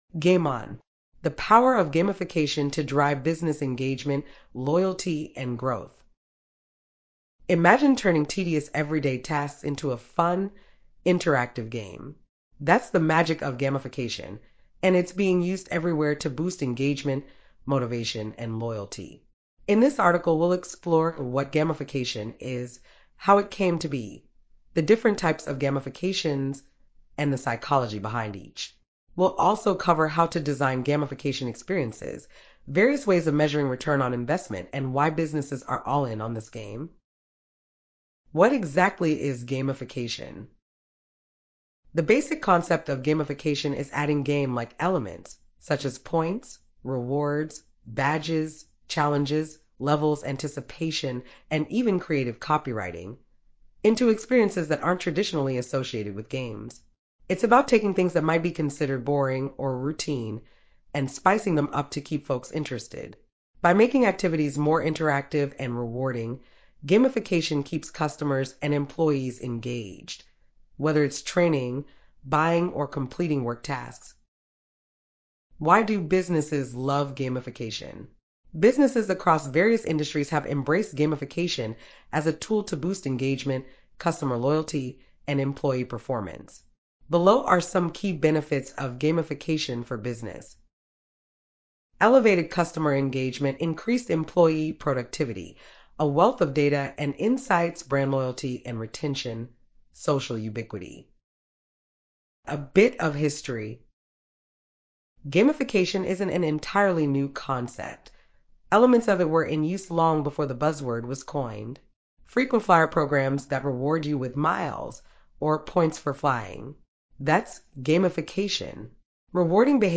You can listen to this article instead. (AI voiceover)